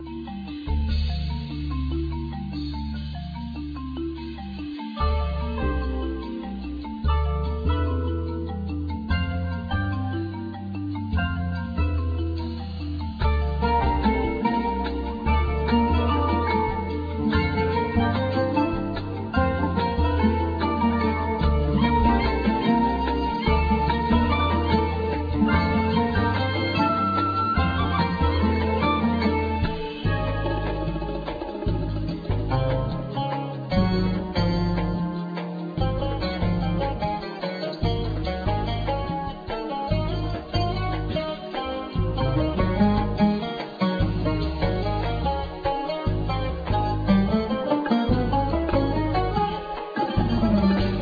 Kanun
Rek,Duf,Zilia,Durbakkeh
Double Bass
Ney
Oud,Vocals,Percussions,Keyboards